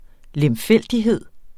Udtale [ lεmˈfεlˀdiˌheðˀ ]